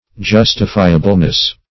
-- Jus"ti*fi`a*ble*ness, n.